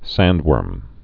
(săndwûrm)